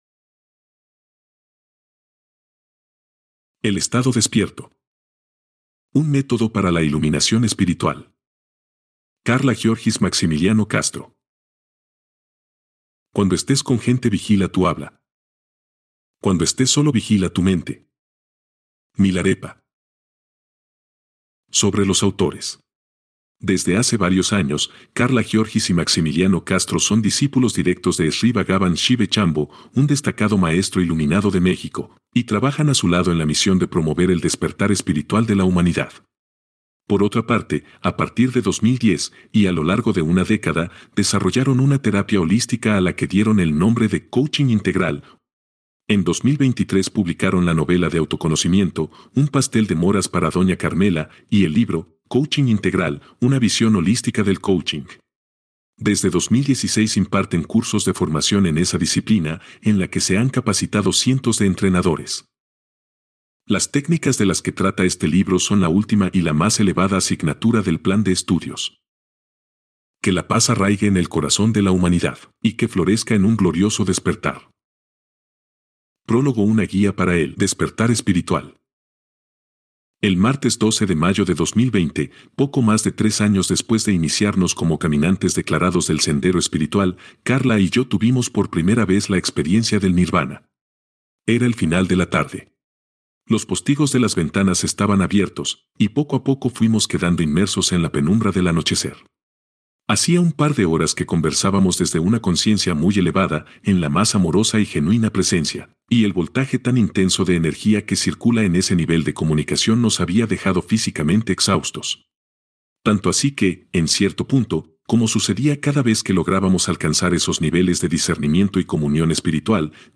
Lectura gratuita de prueba
lectura-de-prueba-EL-ESTADO-DESPIERTO.mp3